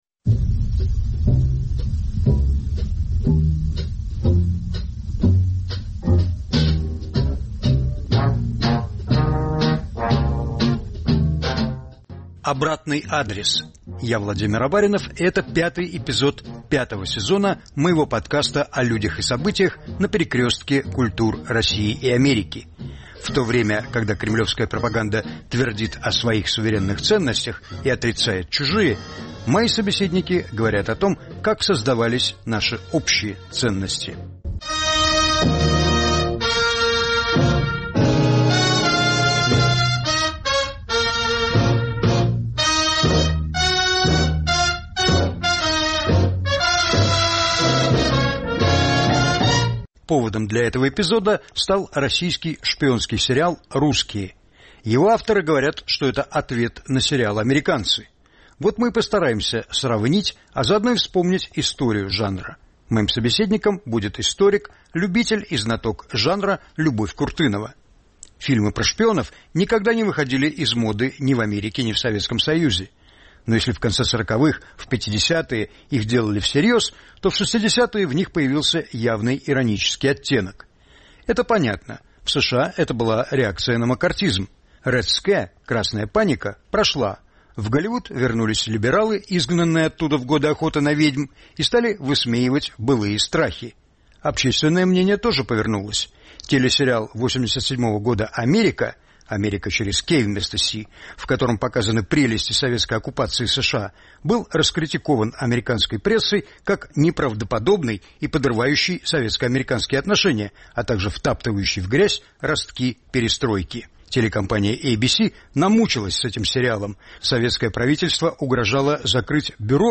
Повтор эфира от 07 апреля 2023 года.